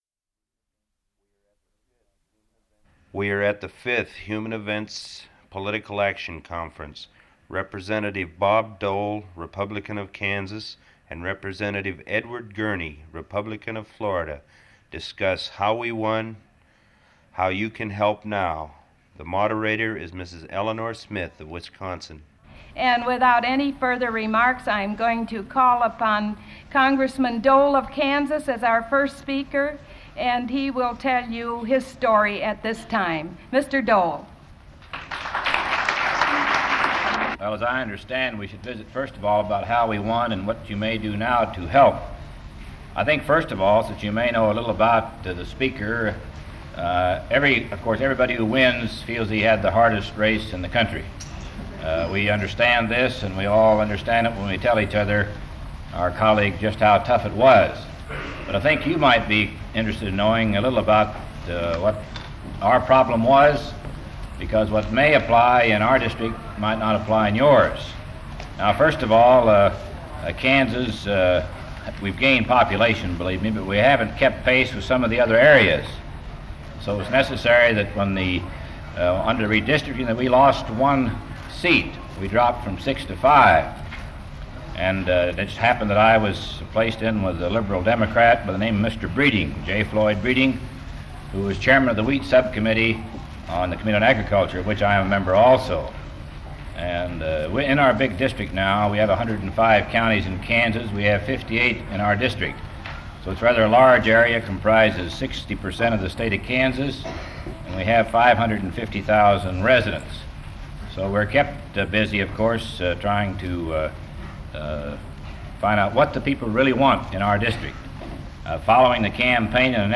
Part of Press Conference of Representatives Bob Dole and Ed Gurney on How They Won